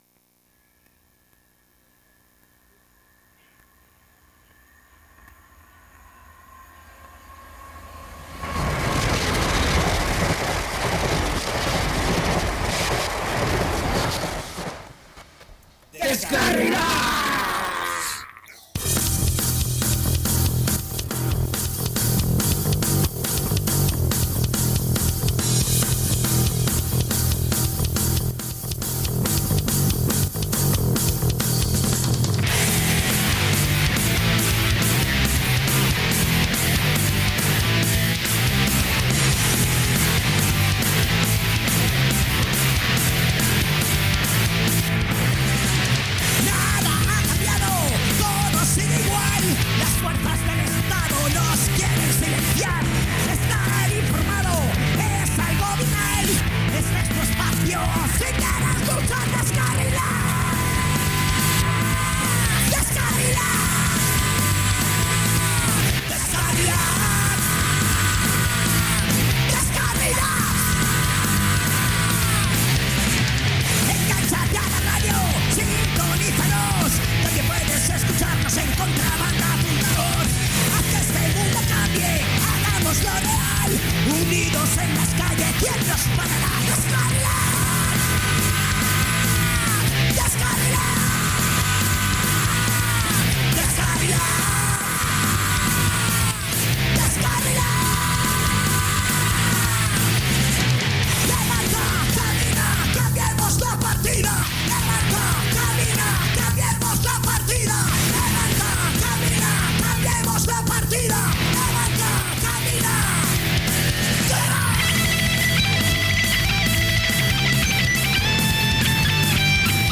Durante el programa vamos poniendo diferentes música skatalitikas y al final sale una entrevista muy guay .